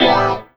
17CHORD02 -L.wav